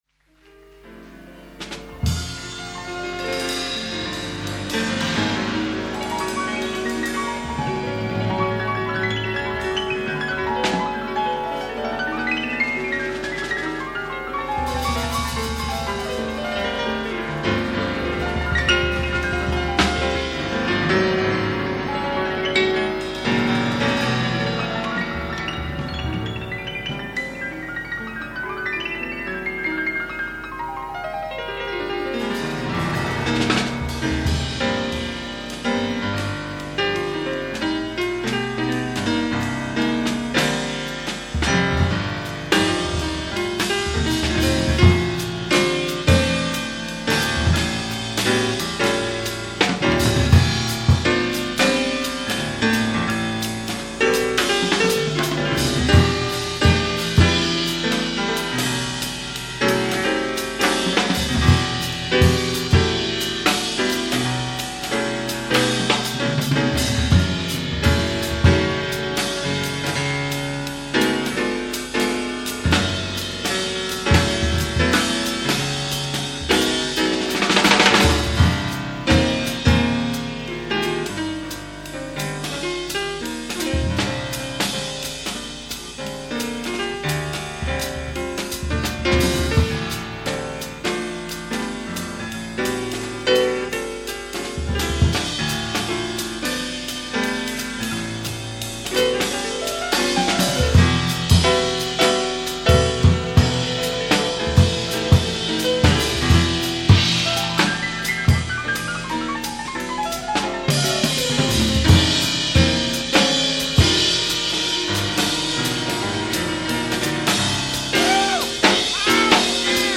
Recorded live at Willisau, Switzerland